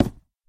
Sound / Minecraft / dig / wood3.ogg
wood3.ogg